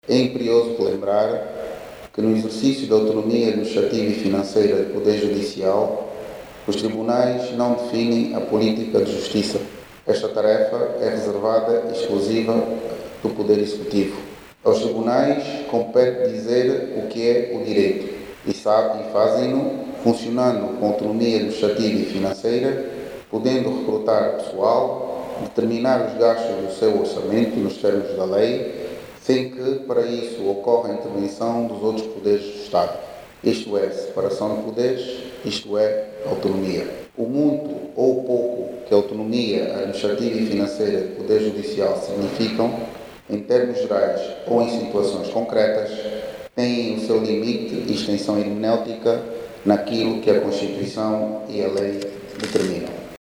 O Ministro da Justiça, Marcy Lopes, que procedeu à abertura do fórum dos Conselhos Superiores de Justiça da Comunidade dos Países de Língua Portuguesa (CPLP), que decorre em Luanda, e, lembrou que os tribunais não definem a política da justiça, uma tarefa reservada ao Poder Executivo.